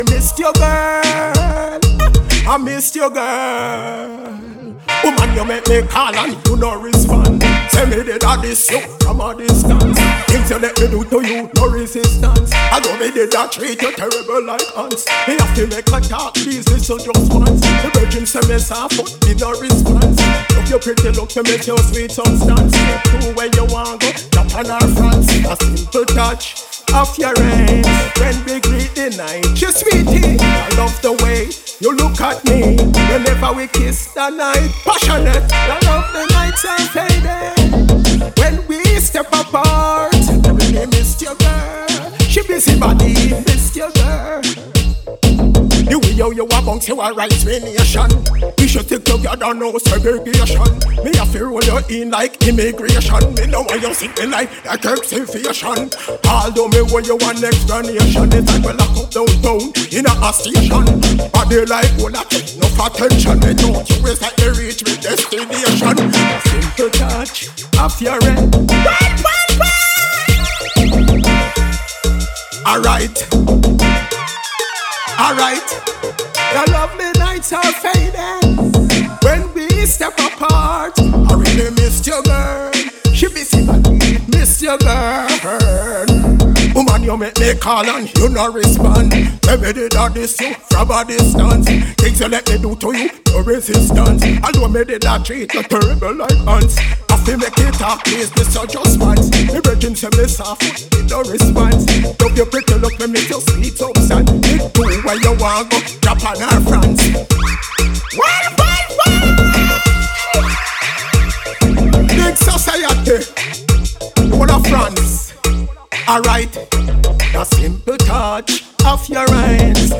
New Release Bass / Dubstep Dancehall